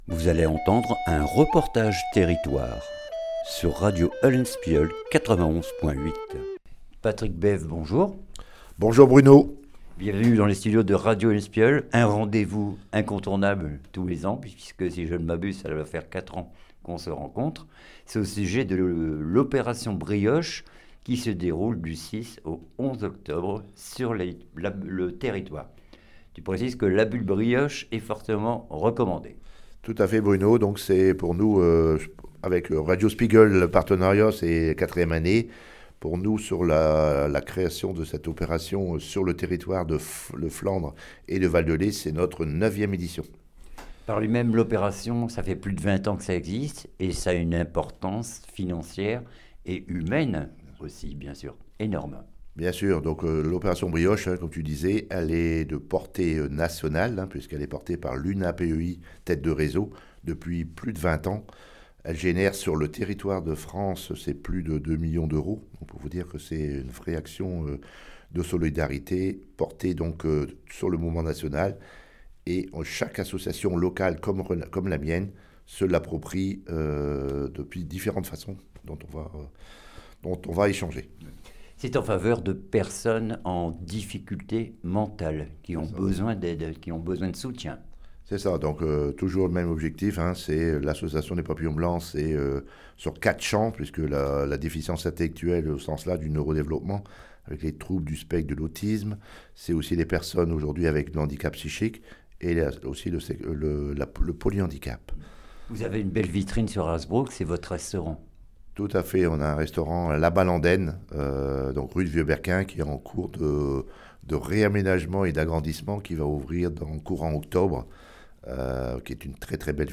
REPORTAGE TERRITOIRE OPERATION BRIOCHES